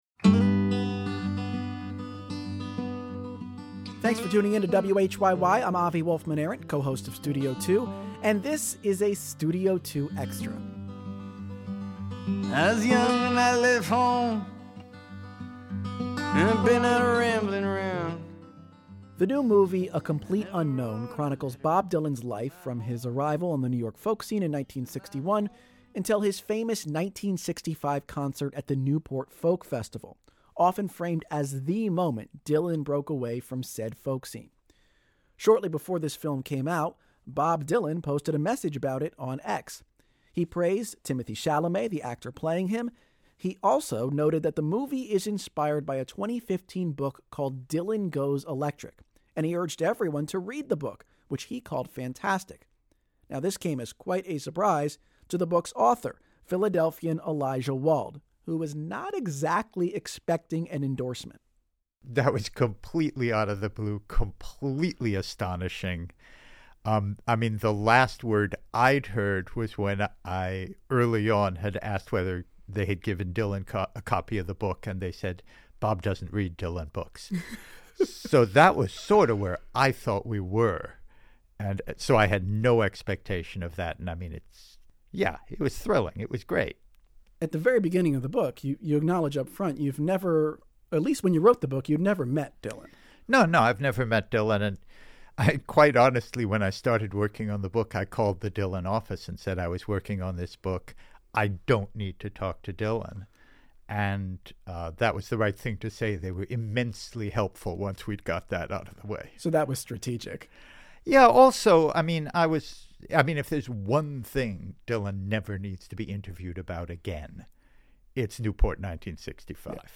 Saxophonist Benny Golson, an “elder statesman of jazz” Air Date: September 5, 2016 Listen 00:48:48 Hour 2 Guest: Benny Golson [From the Radio Times archive] There’s no stopping BENNY GOLSON .
Brought to you by Radio Times Radio Times WHYY's Radio Times is an engaging and timely call-in program that tackles wide-ranging issues of concern to listeners in the Delaware Valley.